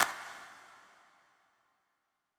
32 Clap Individual+Tail.wav